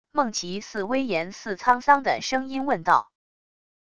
孟奇似威严似沧桑的声音问道wav音频